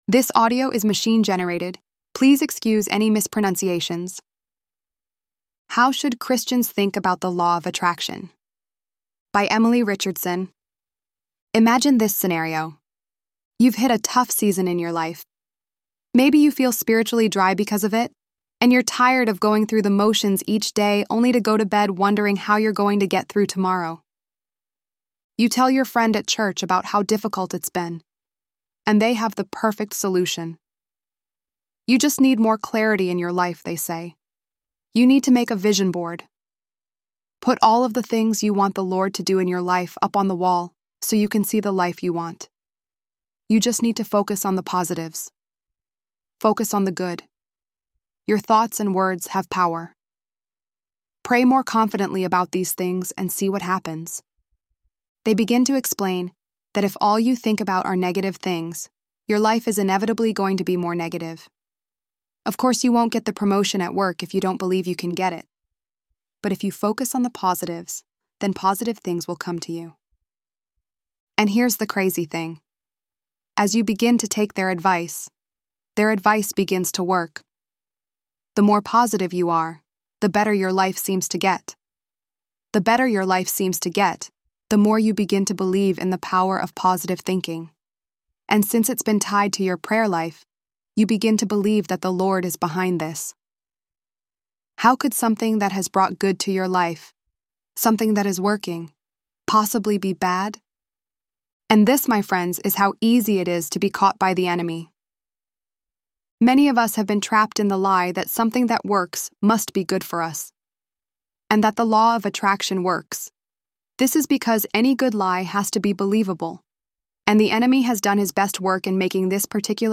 ElevenLabs_5.23_LOA.mp3